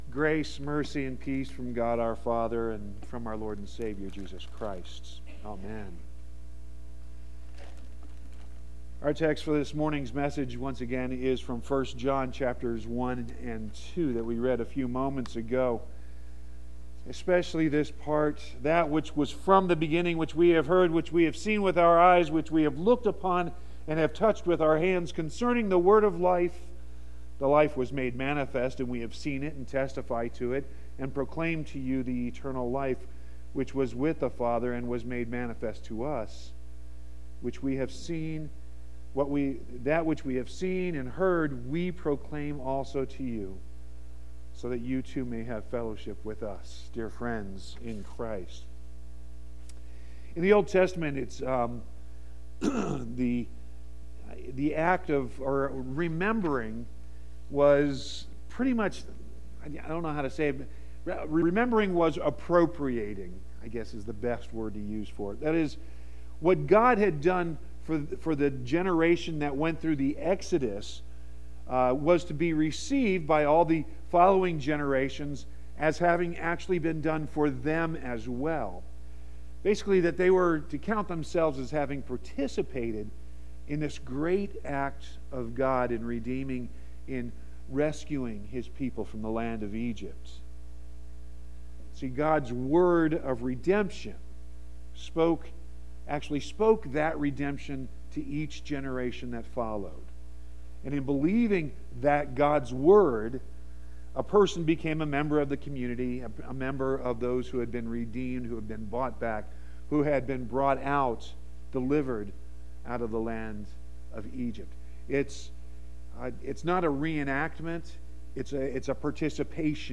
4-8-18-sermon.mp3